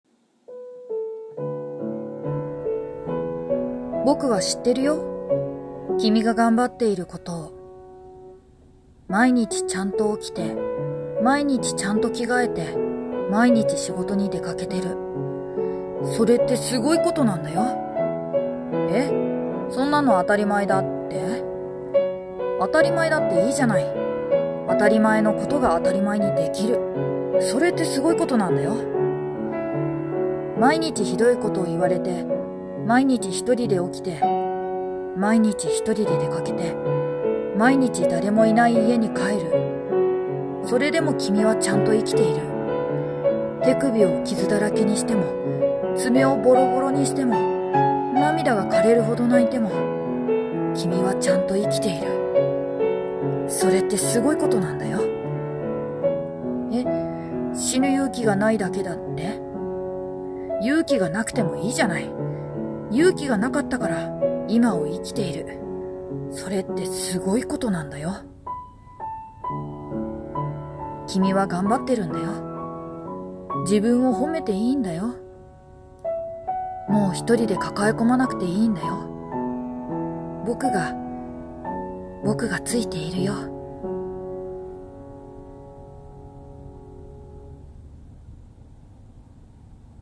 【声劇台本】頑張る君へ